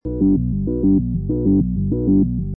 Descarga de Sonidos mp3 Gratis: alarma 16.